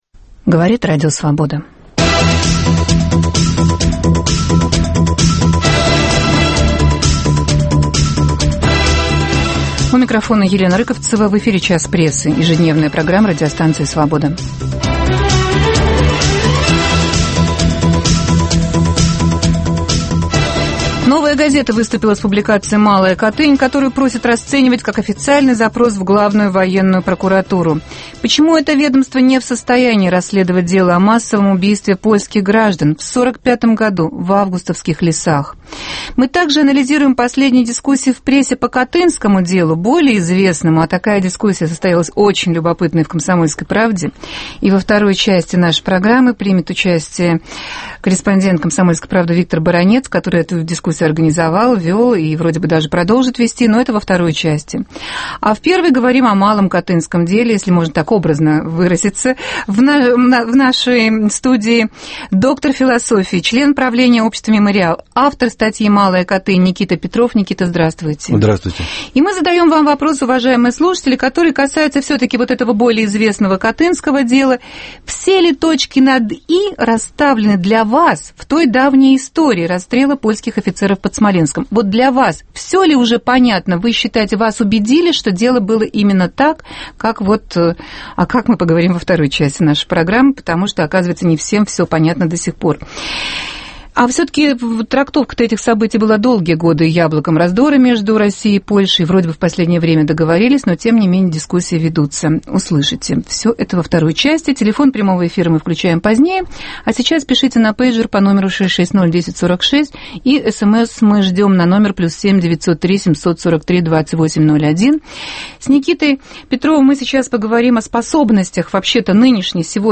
Почему отдельные российские историки упорно отказываются признать виновными в расстреле польских офицеров сотрудников НКВД? Дискутируют доктор философии, член правления общества "Мемориал" Никита Петров и военный обозреватель "Комсомольской правды" Виктор Баранец.